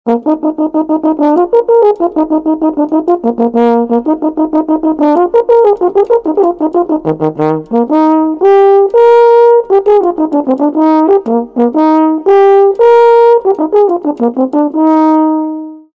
HORNSOLO.mp3